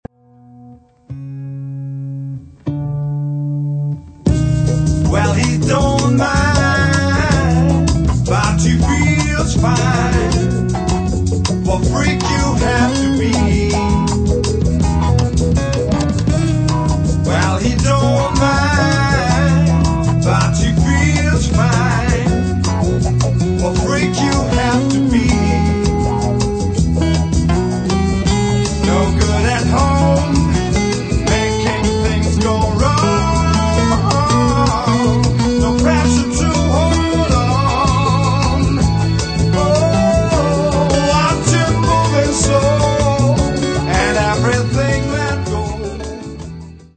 Das klingt doch nach tiefsten Siebziger Jahren!